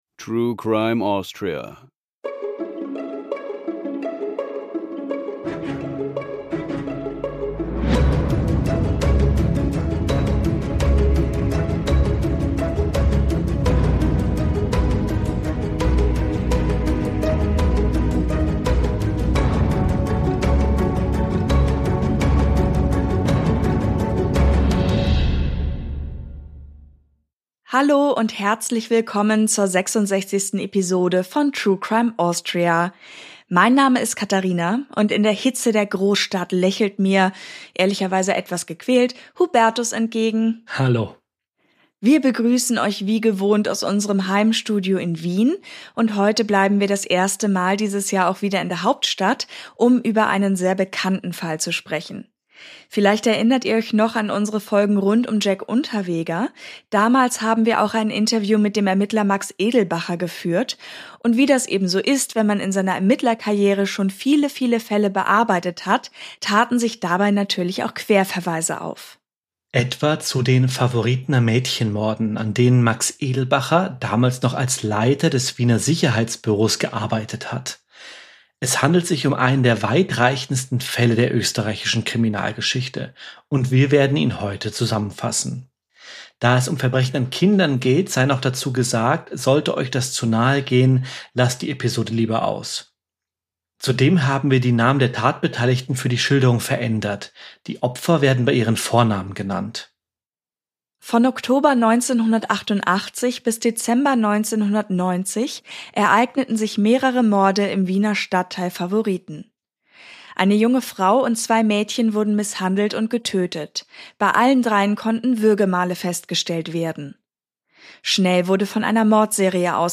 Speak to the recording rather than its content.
1 EPSTEIN HOAX - LIVE CALL IN SHOW!